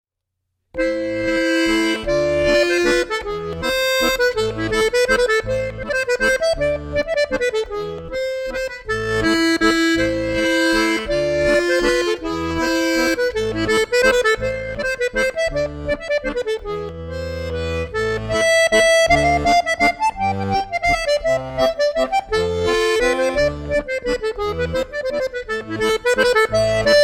danse : valse
Pièce musicale inédite